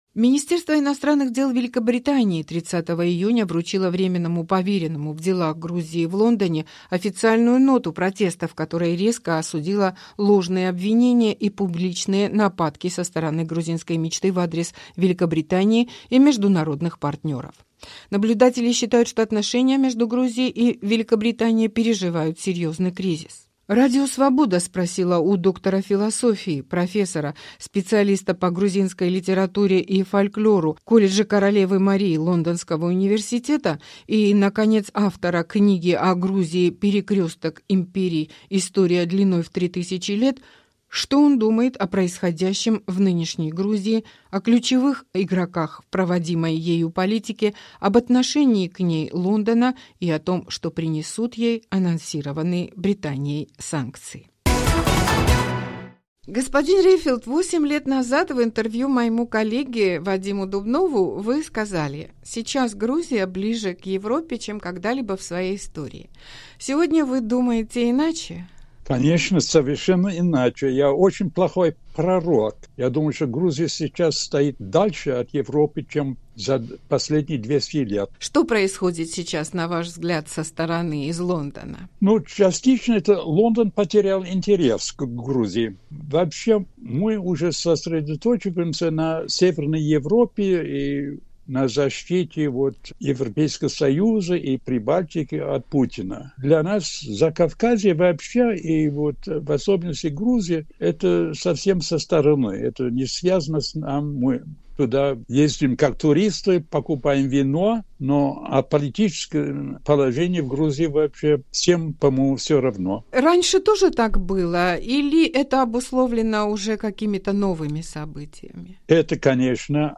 Рубрика "Гость недели", беседы с политиками, экспертами, общественными деятелями